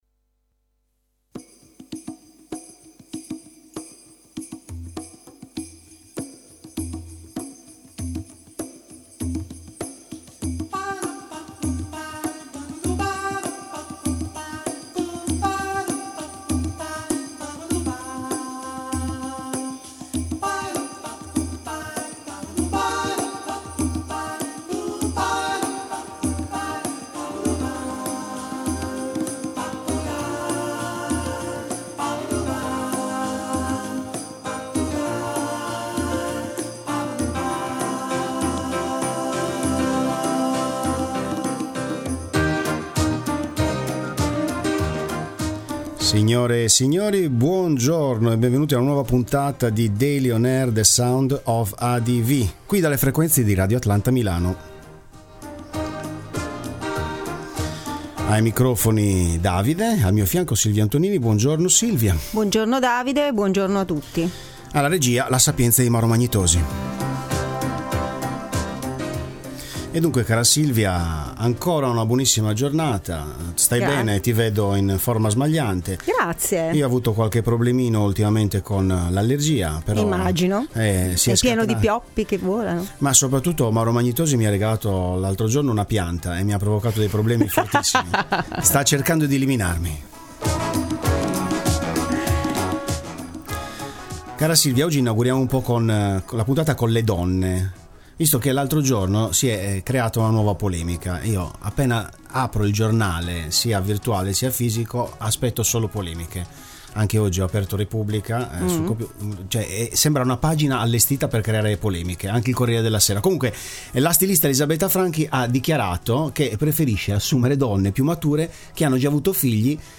l’intervista